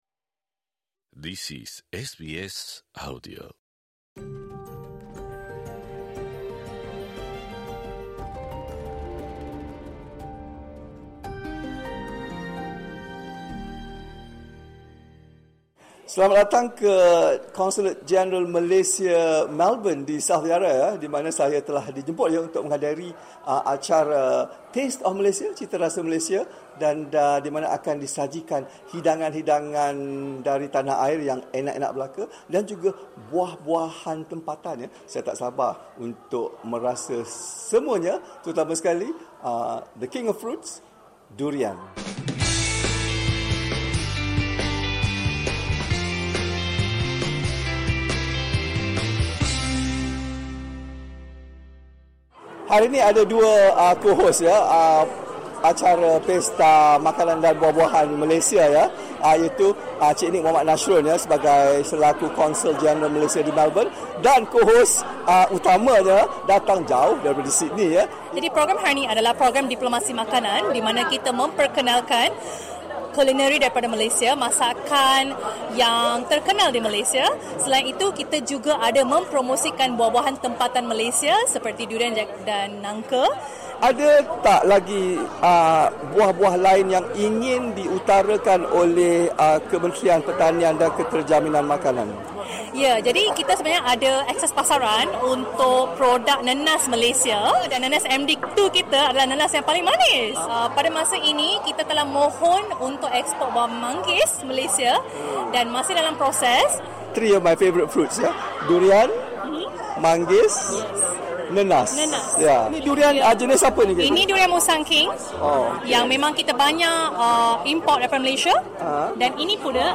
Ikuti perbualan mereka untuk mengetahui apakah lagi buah-buahan tempatan yang bakal dieksport oleh negara Malaysia ke Australia serta perkembangan terkini permohonan Malaysia untuk menyenaraikan Budaya Sarapan Malaysia dalam senarai warisan budaya UNESCO.